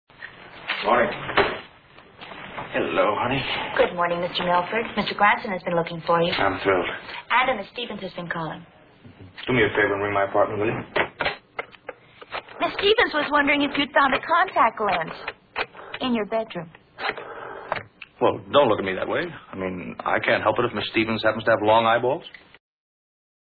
The soundfile below is Bobby's character Tom Milford from the motion picture That Funny Feeling speaking sweetly to his secretary.